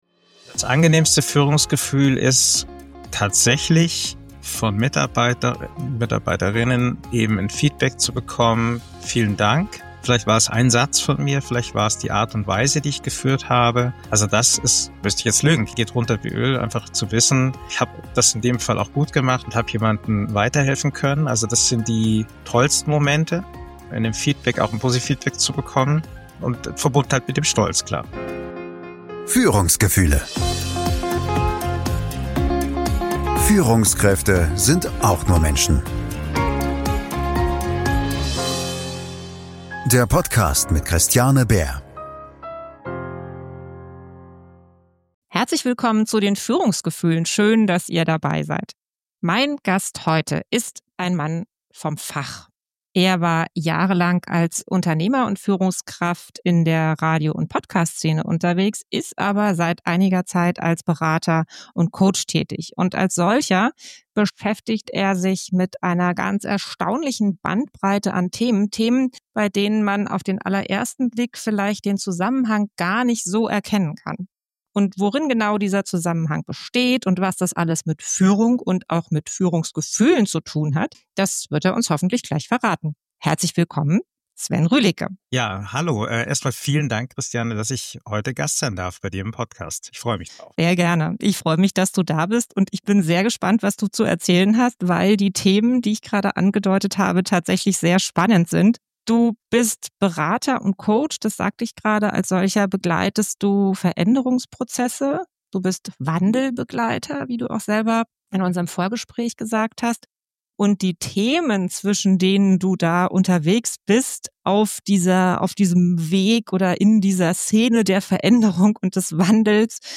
Raus aus dem Rausch – Warum Führung bei dir selbst beginnt - Gespräch